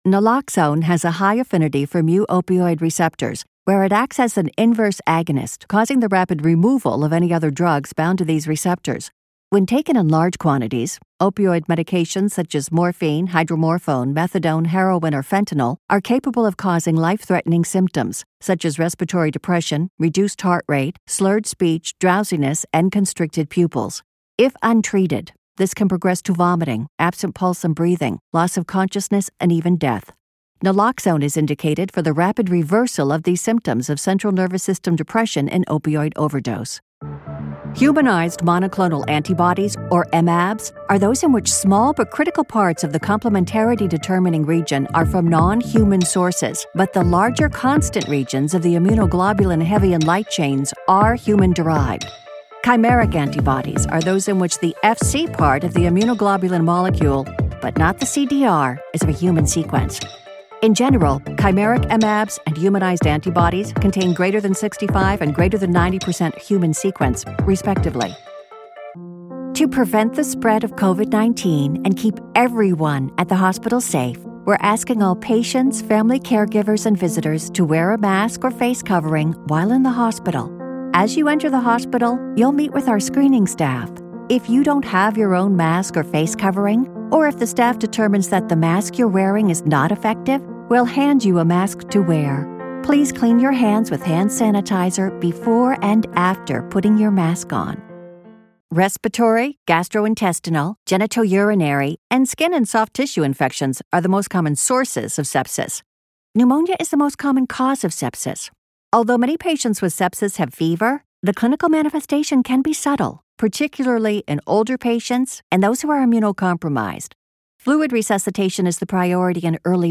Medical Narration complex language, educational, conversational, sweet
Middle Aged